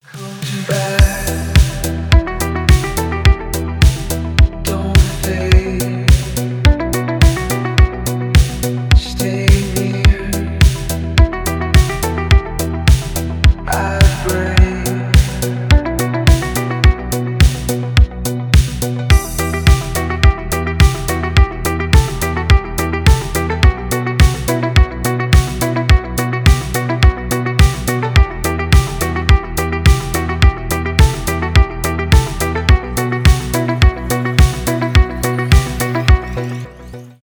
танцевальные , итало диско